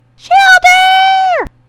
infinitefusion-e18/Audio/SE/Cries/SHELLDER.mp3 at releases-April